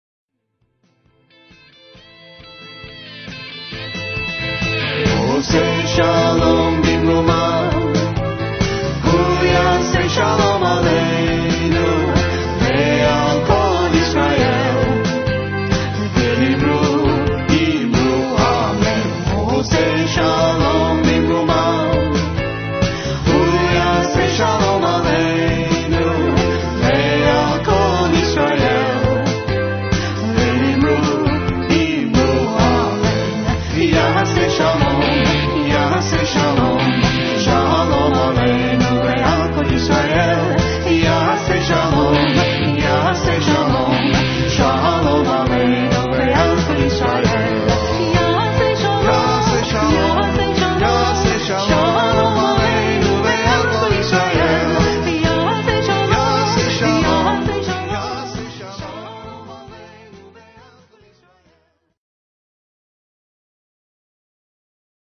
Simcha is the premiere Southwest Florida Jewish music band with an  extensive repertoire of  traditional  Israeli music,